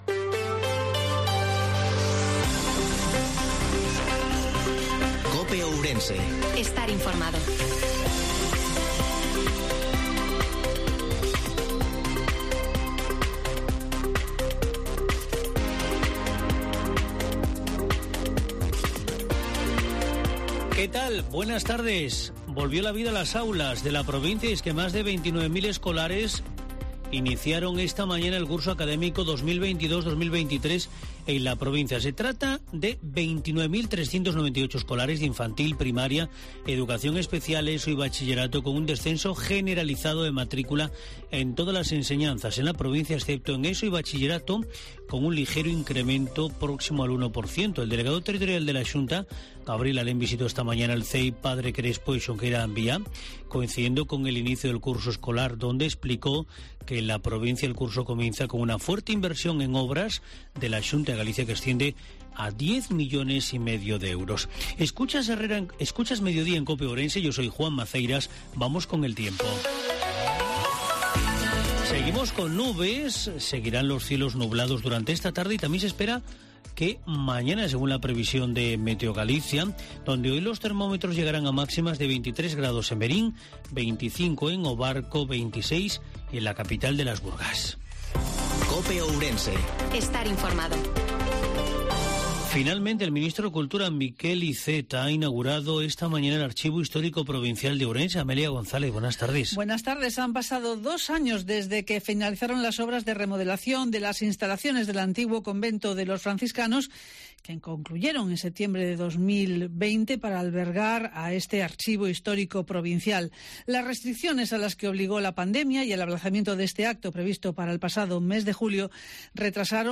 INFORMATIVO MEDIODIA COPE OURENSE-08/09/2022